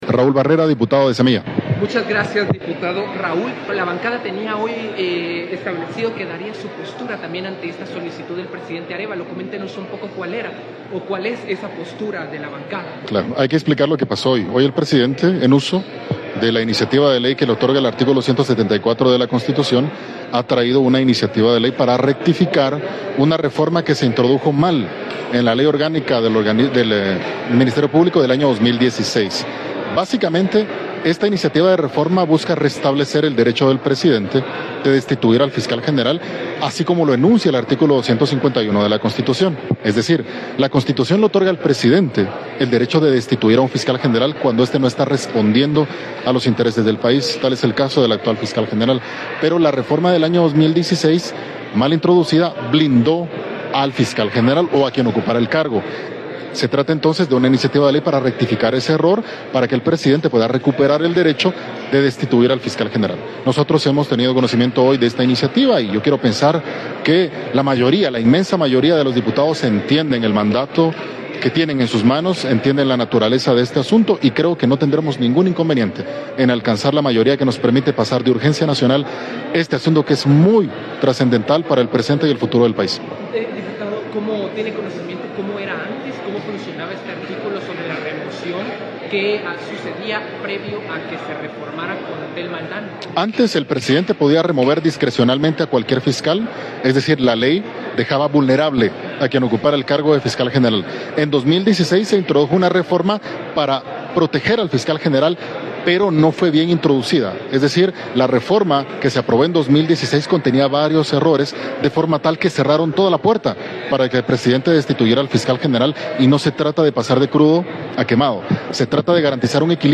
Declaraciones del diputado Raúl Barrera del distrito central.